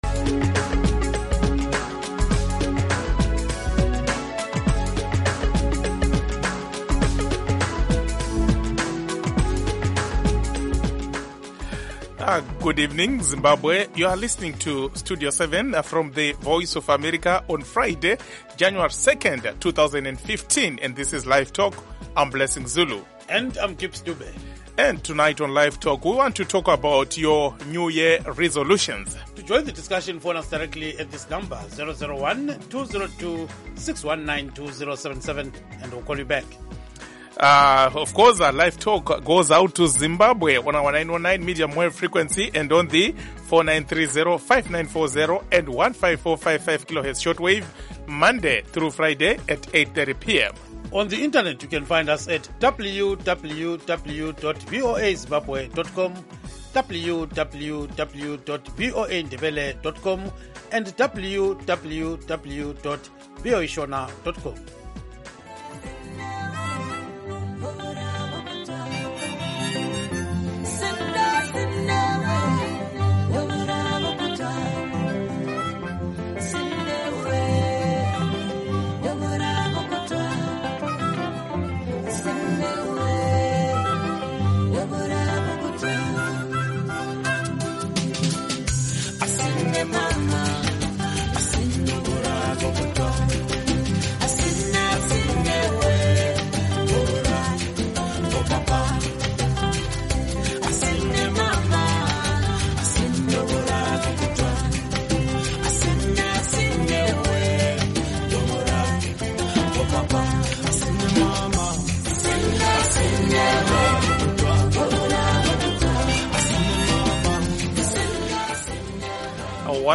Zimbabweans living outside the country who cannot receive our broadcast signals can now listen to and participate in LiveTalk in real time.